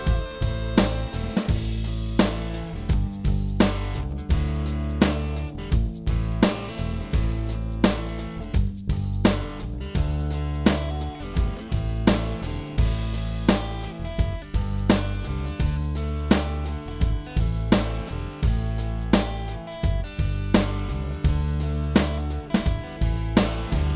Minus Lead Guitar For Guitarists 4:39 Buy £1.50